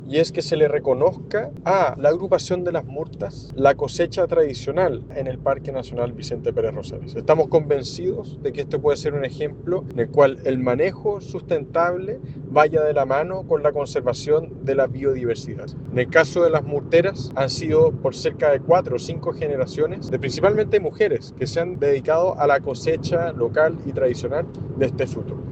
El alcalde de Puerto Varas, Tomás Gárate, impulsor de la iniciativa, destacó la ancestralidad de la práctica.